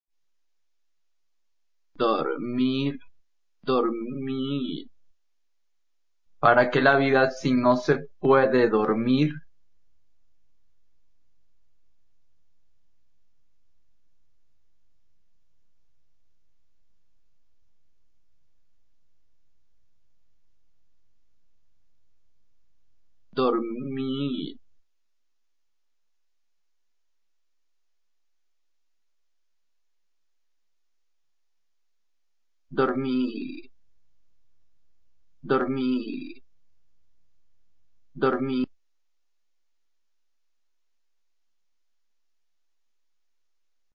aqui la voz del personaje en formato MP3